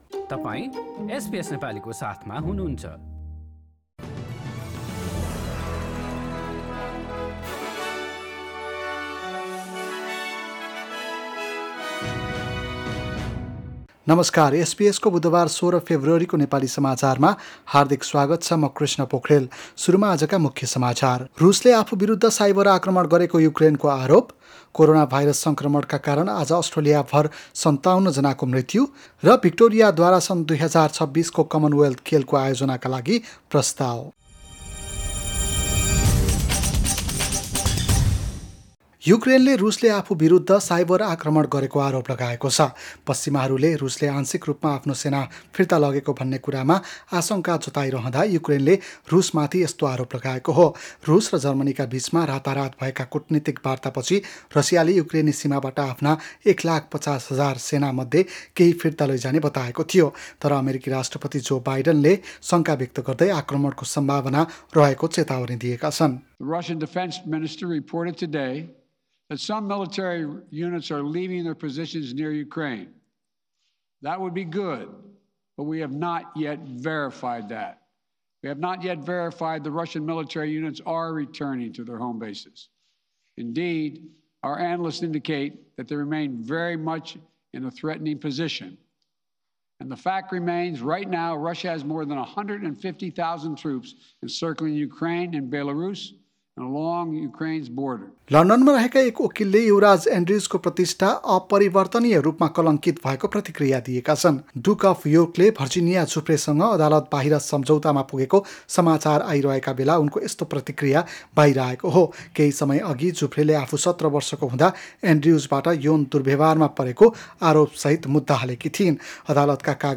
एसबीएस नेपाली अस्ट्रेलिया समाचार: बुधबार १६ फेब्रुअरी २०२२